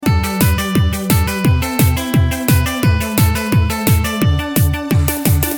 цикличные